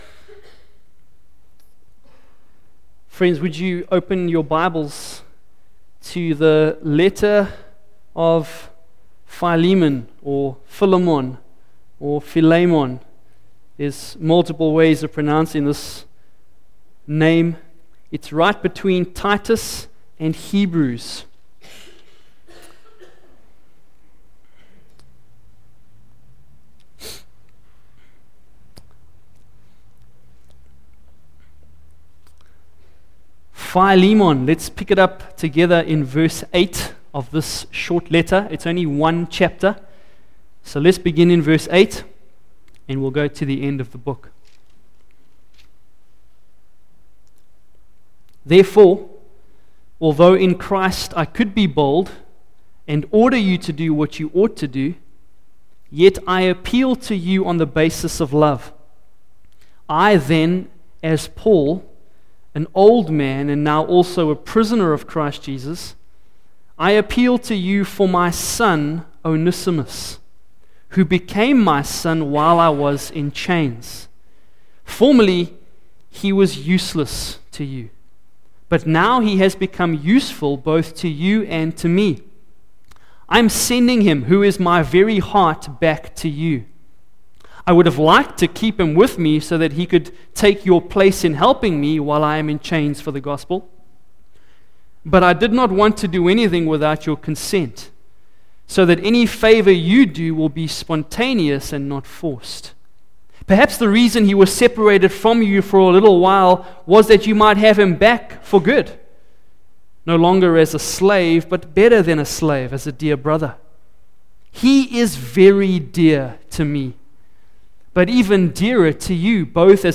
Facebook Twitter email Posted in Morning Service